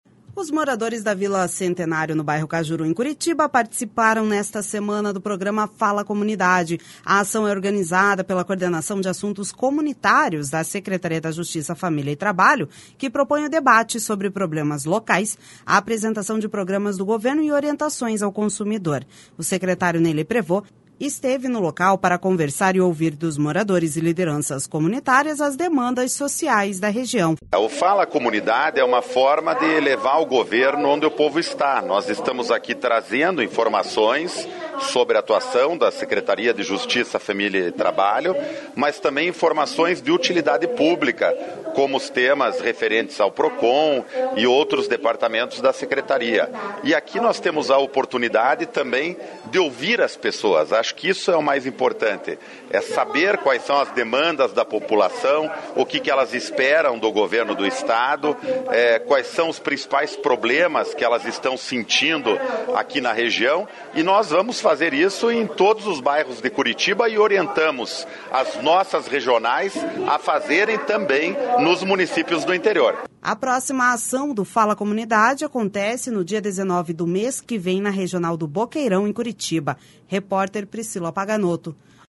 O secretário Ney Leprevost esteve no local para conversar e ouvir dos moradores e lideranças comunitárias as demandas sociais da região. // SONORA NEY LEPREVOST // A próxima ação do Fala Comunidade acontece no dia 19 do mês que vem, na Regional do Boqueirão, em Curitiba.